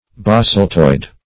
Basaltoid \Ba*salt"oid\, a.